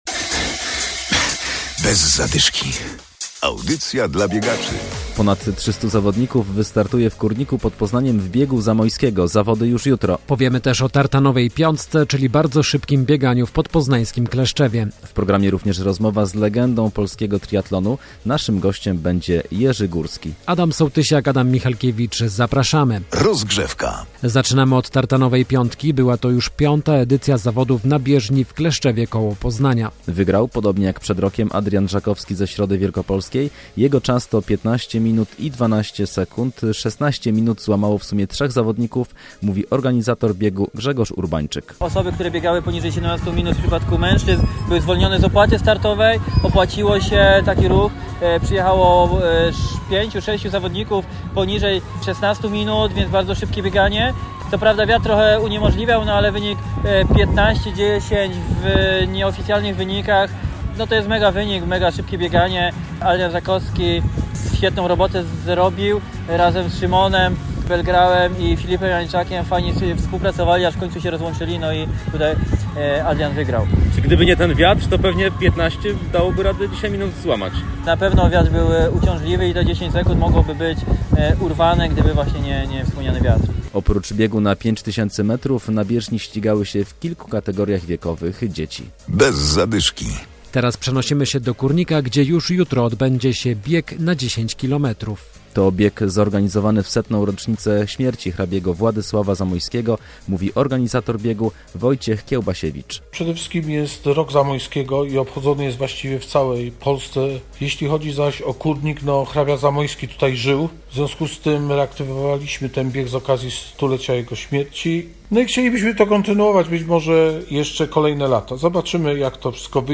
Rozmowa z legendą polskiego triathlonu Jerzym Górskim. Relacja z Tartanowej Piątki oraz zaproszenie na Bieg Zamojskiego do Kórnika.